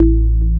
54_25_organ-A.wav